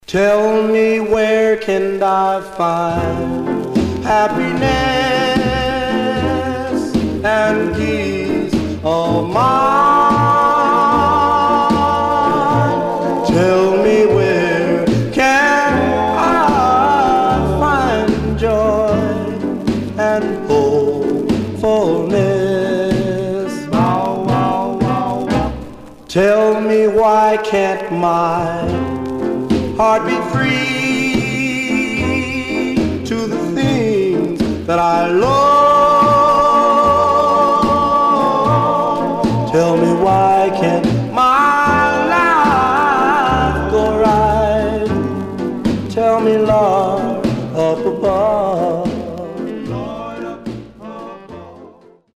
Stereo/mono Mono
Male Black Groups